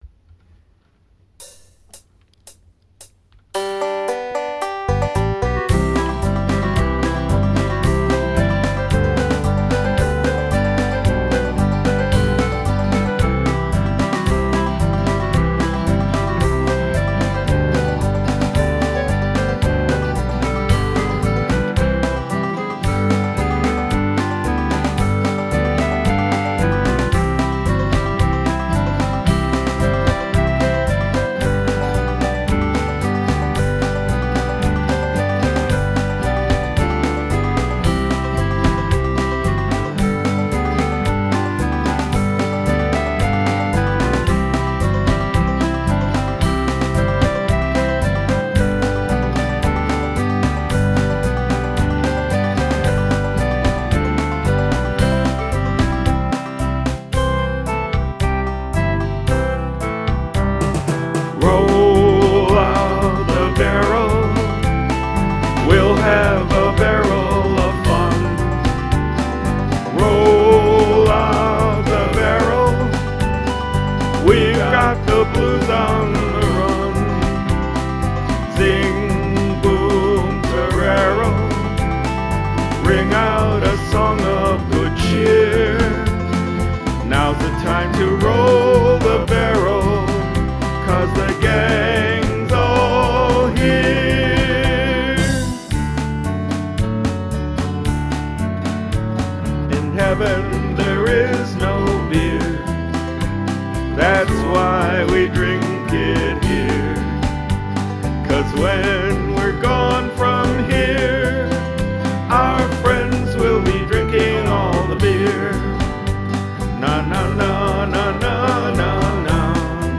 3:34 - k:E,A,D